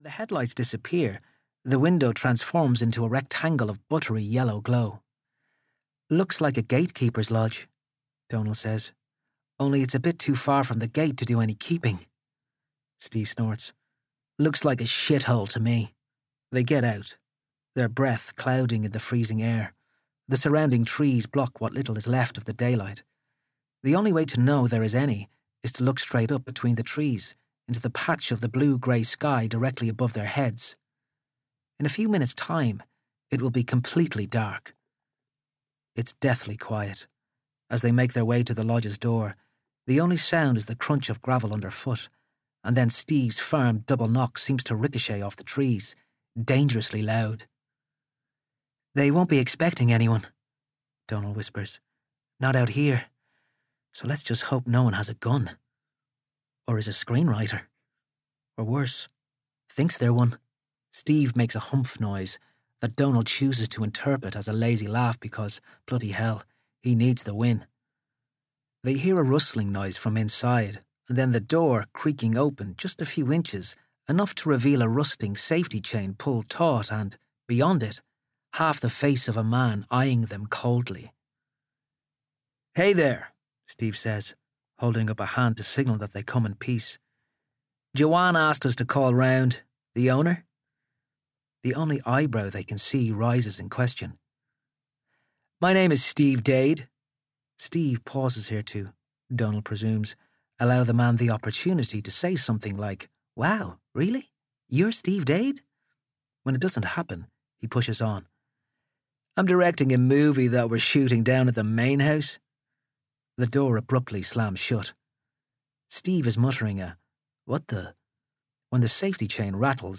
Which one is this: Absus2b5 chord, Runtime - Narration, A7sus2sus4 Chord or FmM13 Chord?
Runtime - Narration